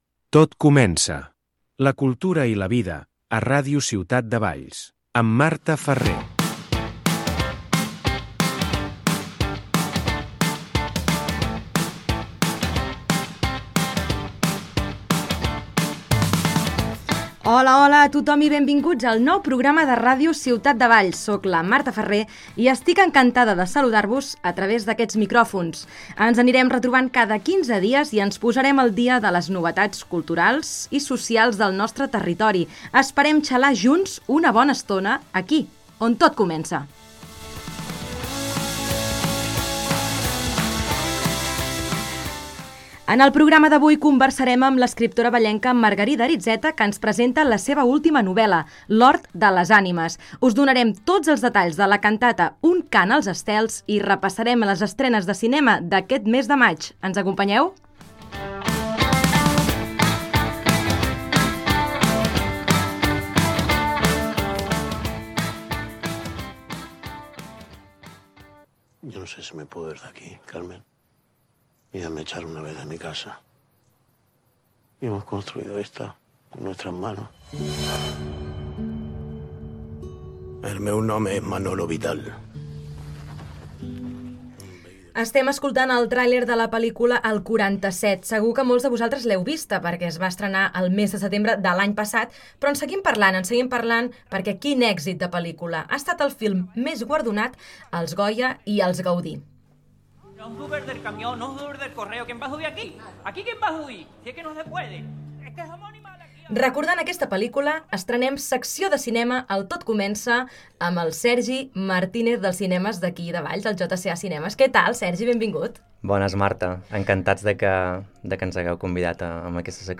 En el primer capítol, el programa entrevista l’escriptora Margarida Aritzeta, que parla de la seva última novel·la, L’Hort de les Ànimes, entre d’altres qüestions.